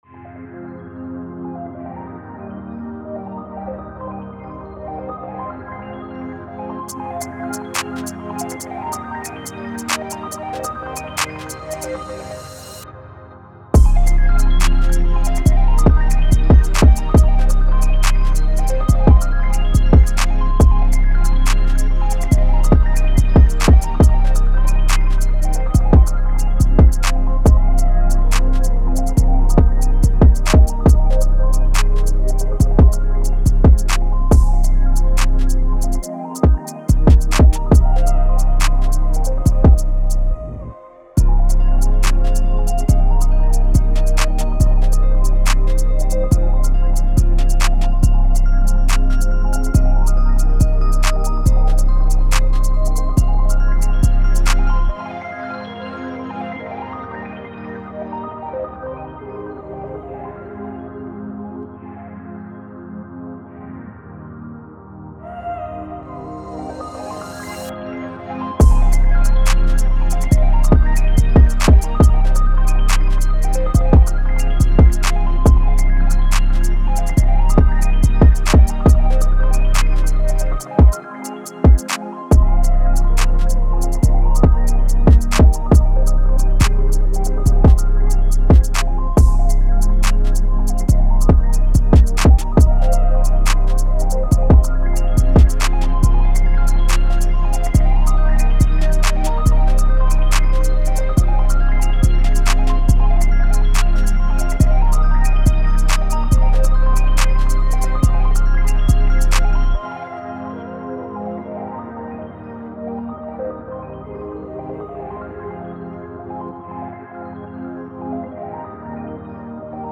Cold, Energetic, Sexy
Drum, Heavy Bass, Piano, Strings